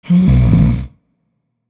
inhale.wav